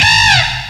pokeemerald / sound / direct_sound_samples / cries / archen.aif
-Replaced the Gen. 1 to 3 cries with BW2 rips.